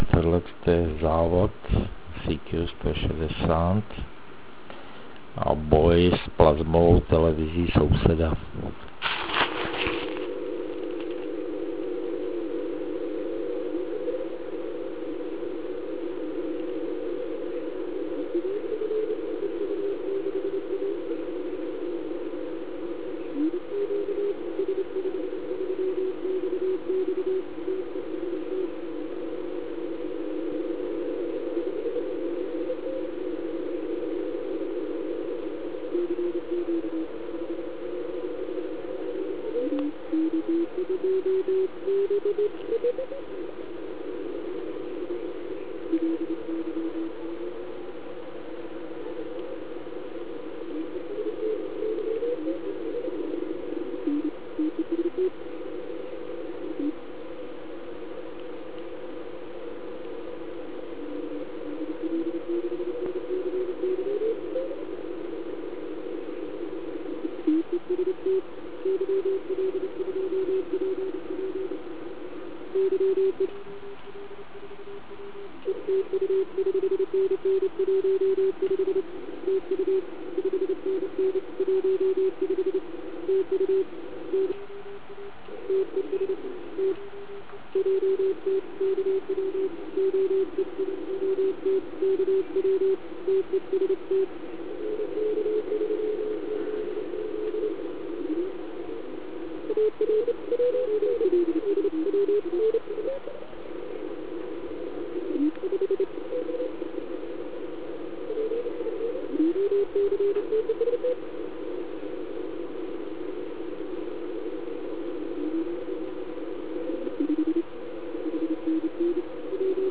Ale abych Vám předvedl, co jsou plazmové TV, pořídil jsem jen jeden záznam. Zkrátka hrůza.